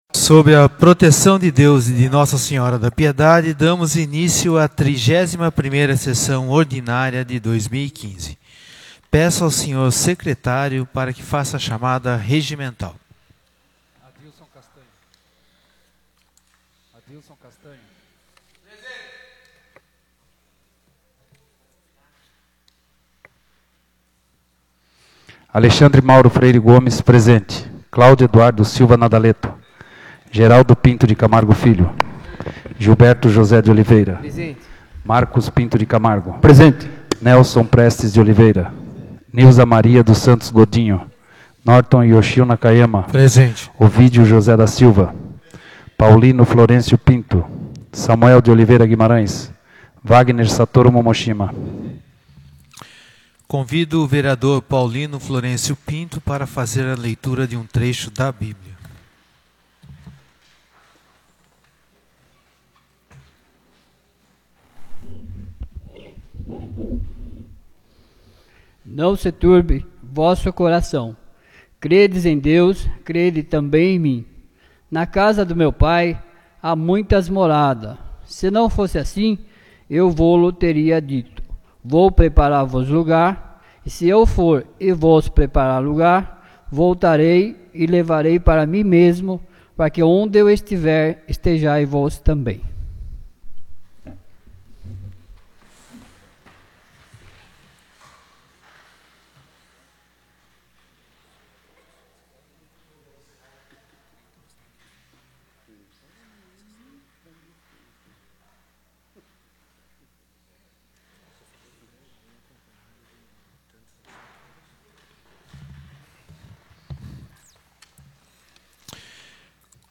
31ª Sessão Ordinária de 2015